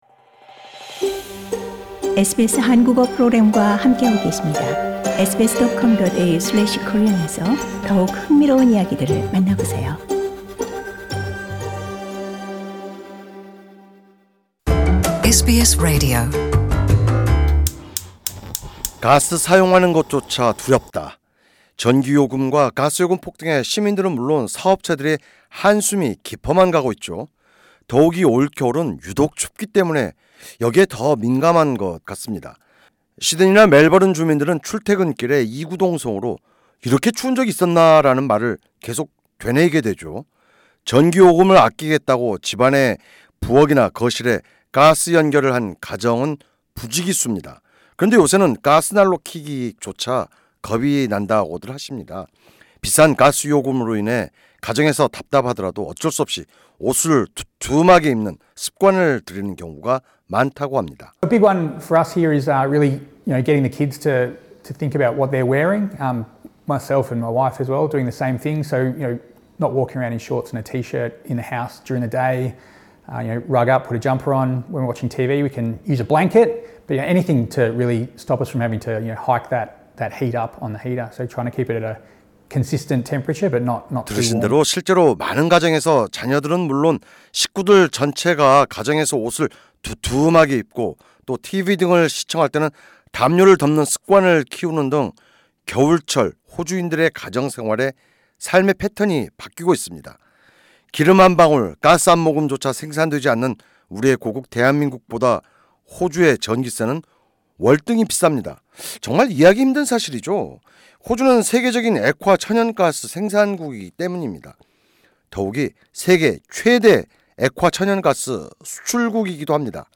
연방 정부, 주와 테러토리 장관 회의 개최 크리스 보원 연방에너지부 장관의 말을 들어보시겠습니다.